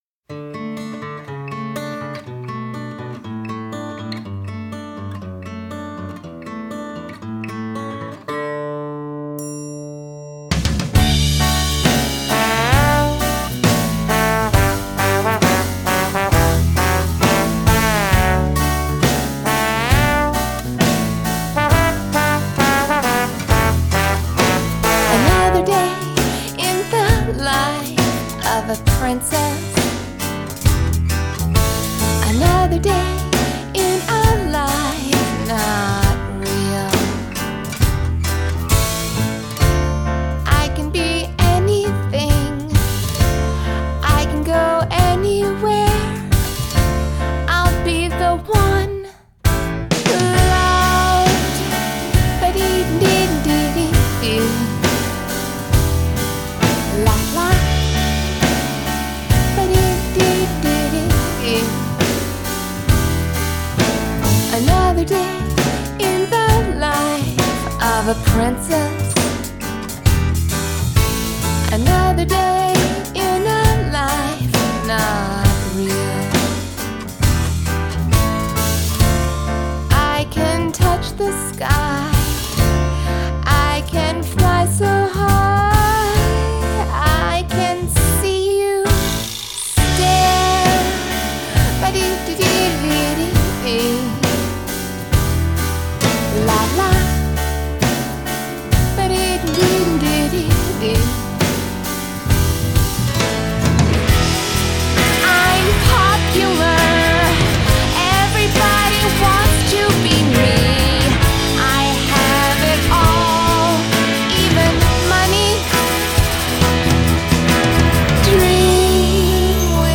Adult Contemporary
Indie Pop , Musical Theatre